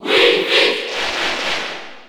Category:Crowd cheers (SSB4) You cannot overwrite this file.
Wii_Fit_Trainer_Cheer_French_PAL_SSB4.ogg